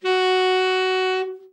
Index of /90_sSampleCDs/Giga Samples Collection/Sax/TEN SAX SOFT